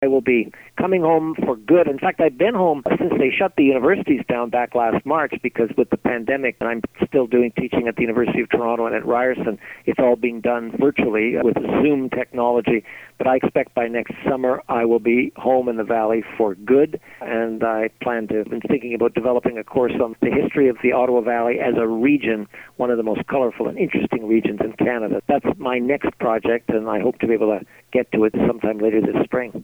Sean Conway Order of Ontario interview